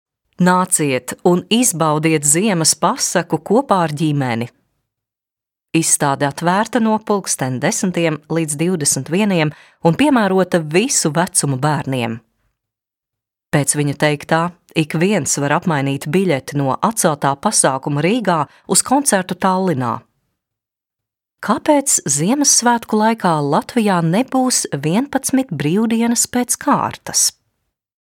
Französisch Monolog